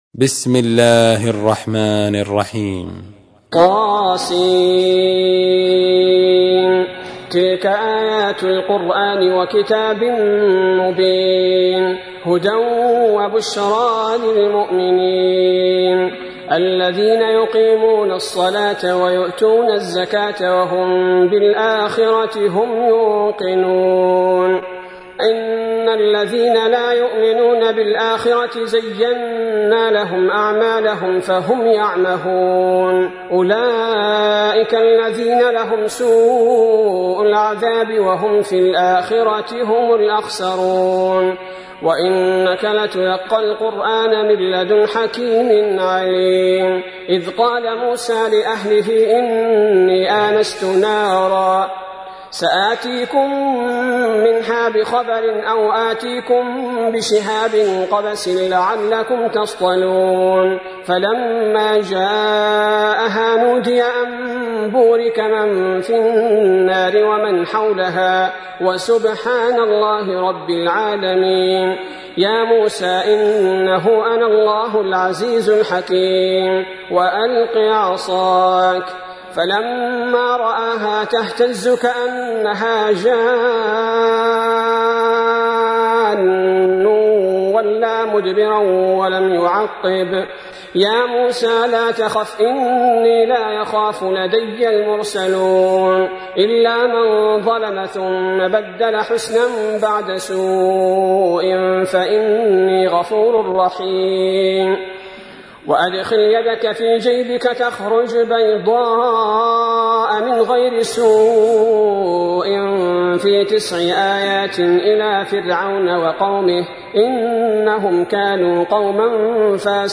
تحميل : 27. سورة النمل / القارئ عبد البارئ الثبيتي / القرآن الكريم / موقع يا حسين